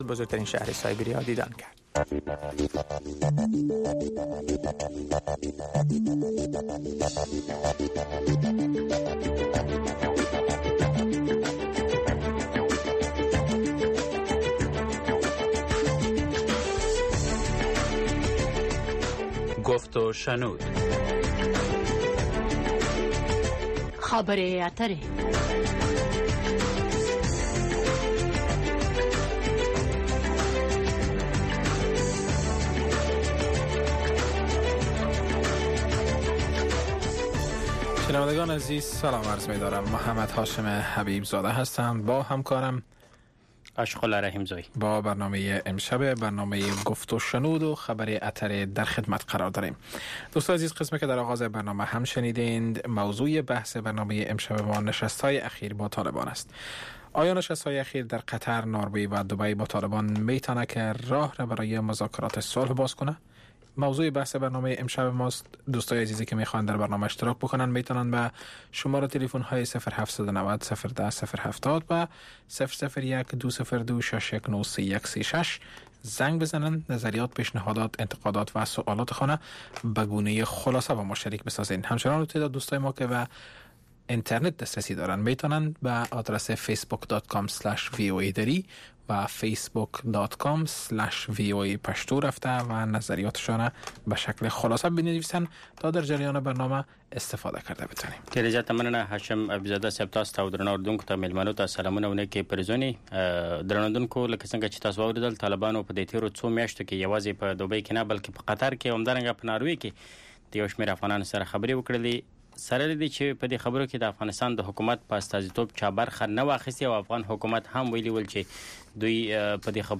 گفت و شنود - خبرې اترې، بحث رادیویی در ساعت ۰۸:۰۰ شب به وقت افغانستان به زبان های دری و پشتو است. در این برنامه، موضوعات مهم خبری هفته با حضور تحلیلگران و مقام های حکومت افغانستان به بحث گرفته می شود.